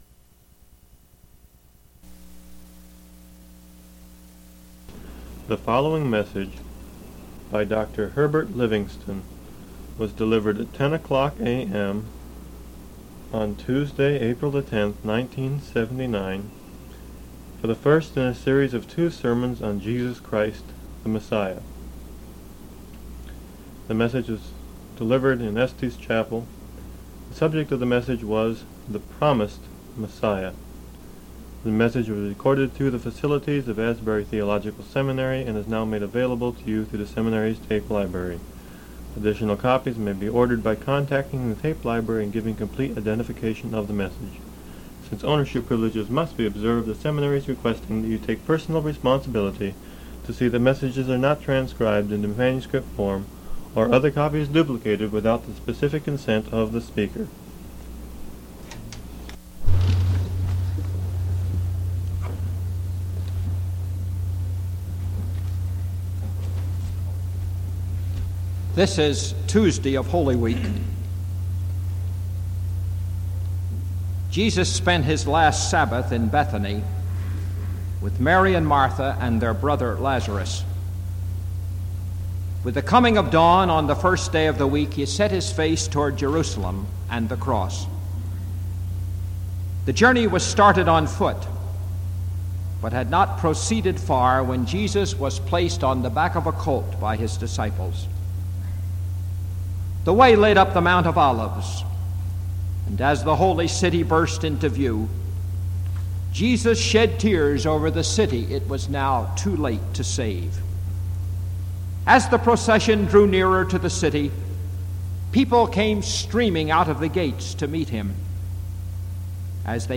Faculty chapel services, 1979